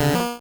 Cri de Ramoloss dans Pokémon Rouge et Bleu.